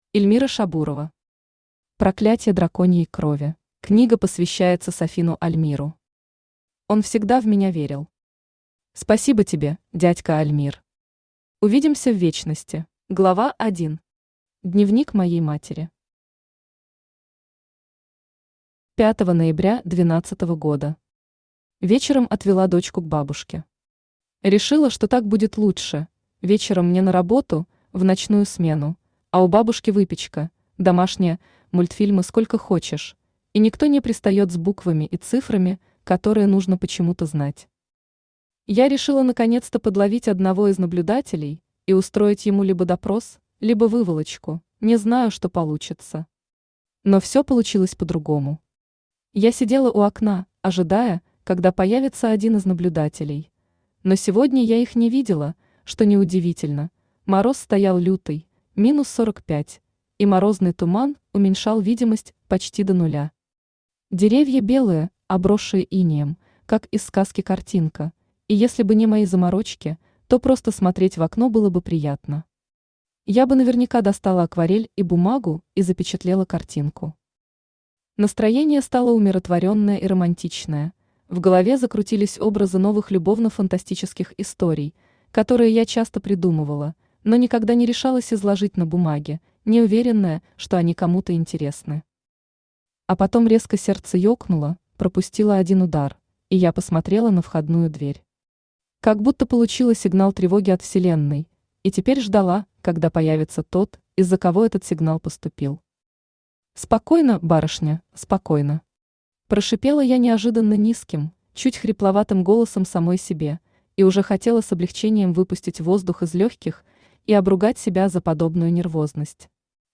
Аудиокнига Проклятье драконьей крови | Библиотека аудиокниг
Aудиокнига Проклятье драконьей крови Автор Эльмира Шабурова Читает аудиокнигу Авточтец ЛитРес.